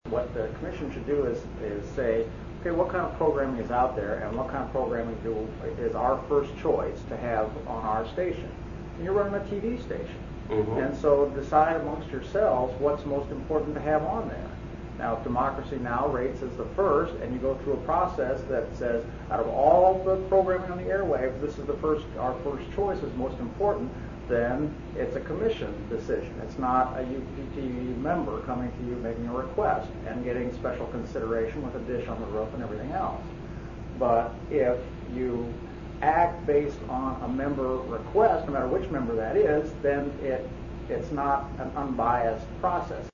Audio clip from the meeting: